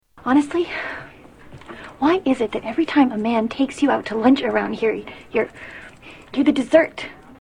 Category: Television   Right: Personal
Tags: Mad Men Mad Men clips Peggy Olson Elisabeth Moss TV series